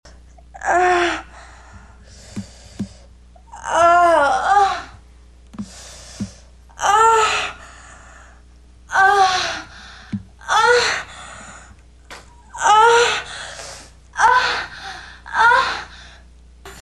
Категория: Живые звуки, имитация